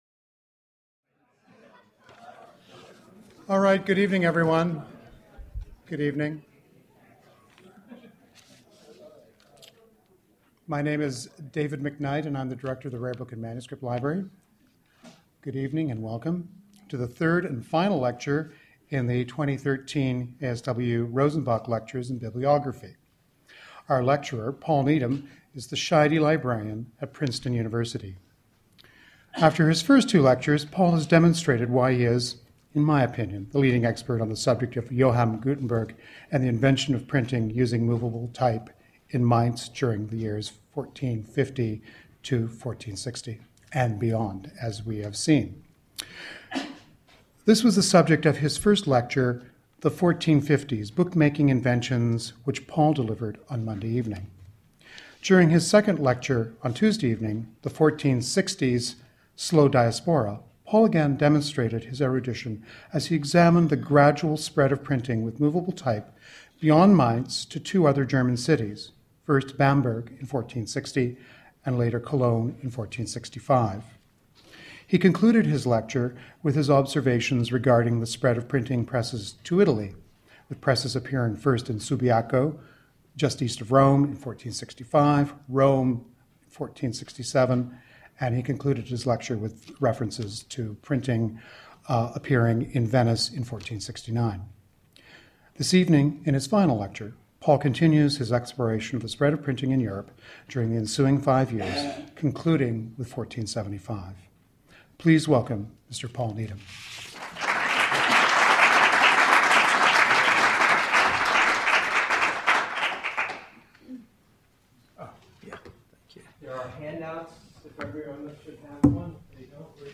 Question and Answer